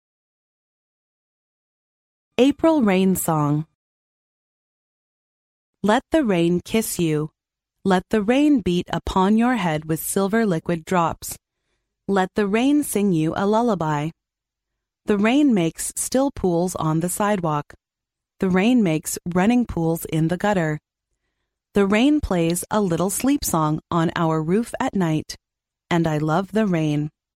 幼儿英语童谣朗读 第51期:四月的雨 听力文件下载—在线英语听力室